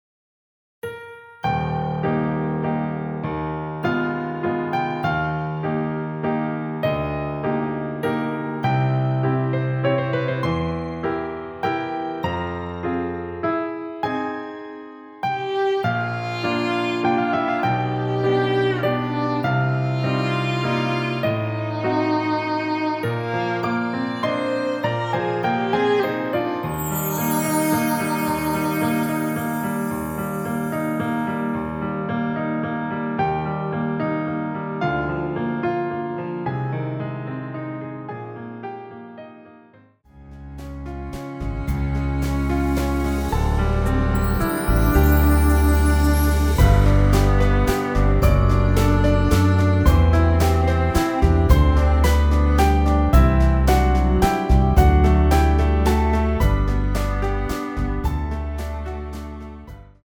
원키에서(-3)내린 MR입니다.
Eb
앞부분30초, 뒷부분30초씩 편집해서 올려 드리고 있습니다.
중간에 음이 끈어지고 다시 나오는 이유는